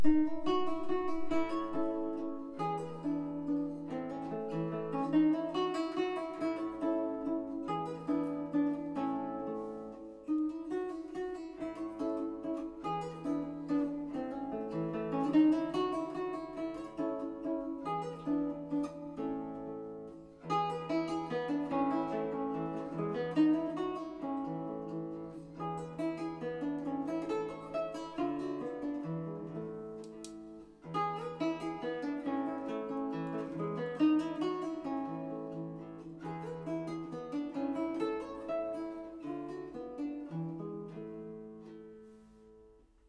Il suono robusto e squillante unito ad una particolare chiarezza nel registro medio ed una uniformità timbrica la rendono particolarmente adatta alle sonorità del repertorio della Hausmusik Viennese.
Chitarra, J. Klimits